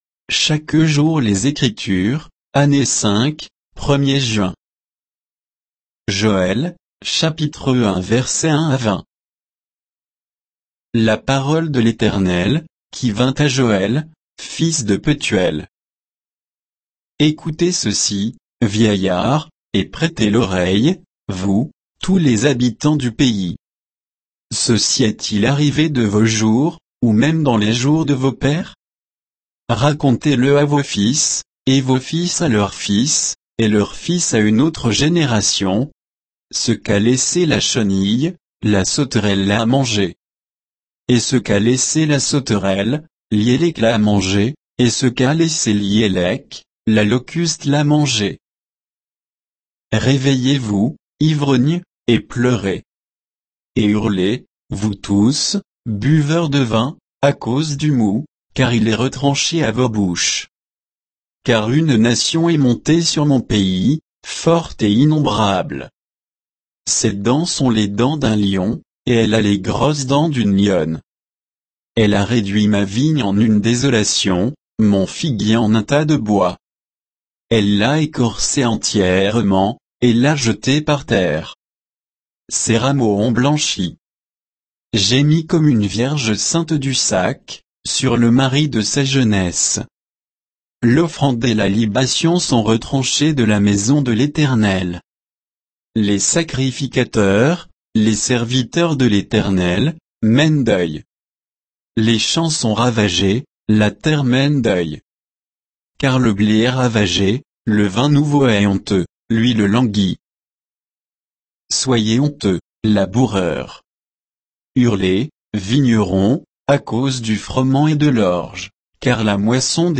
Méditation quoditienne de Chaque jour les Écritures sur Joël 1, 1 à 20